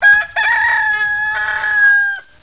Silly Sound Files - Roosters
Rooster 4 - 27kb
rooster4.wav